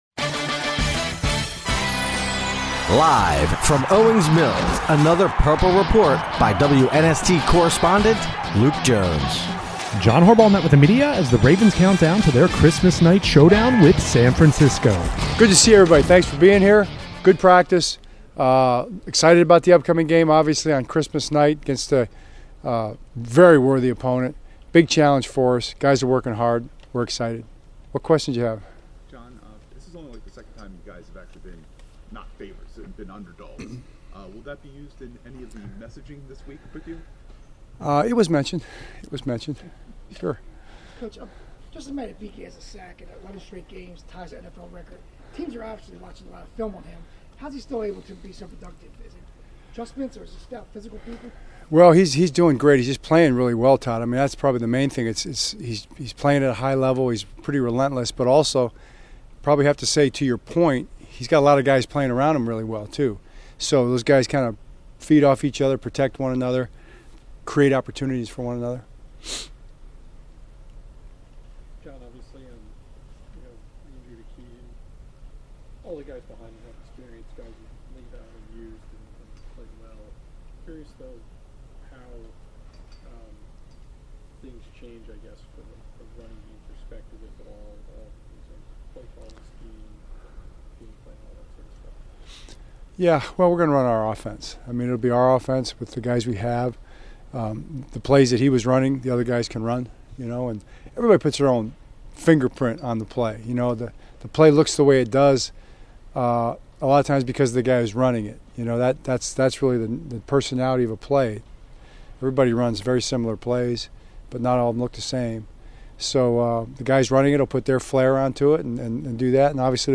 John Harbaugh discusses challenges of slowing explosive San Francisco offense
Locker Room Sound